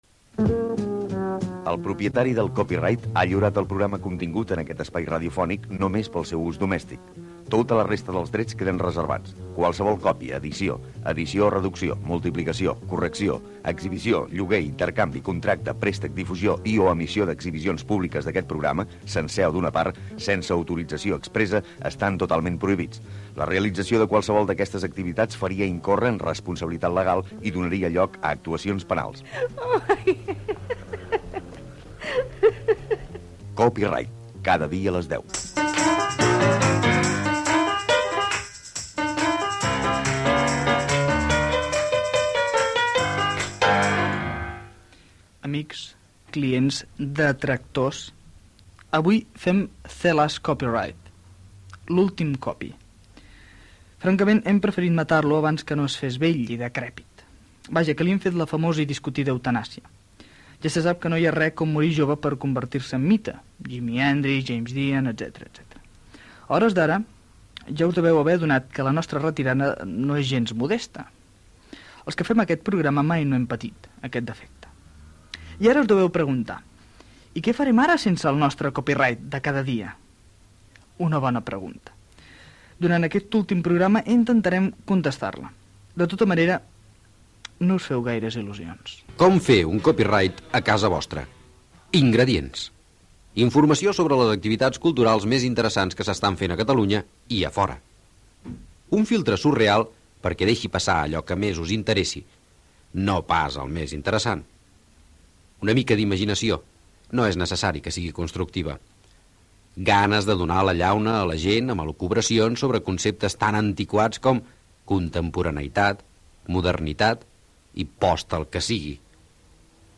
Careta del programa, presentació de l'últim programa, explicació de com fer i com fer sevir un "Copyright" a casa
FM